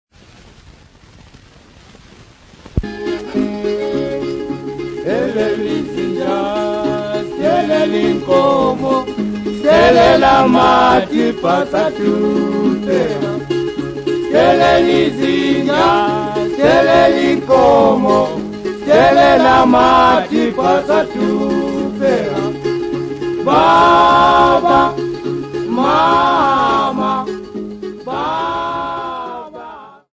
Folk Music
Field recordings
Africa Zimbabwe Bulawayo f-rh
sound recording-musical
Indigenous music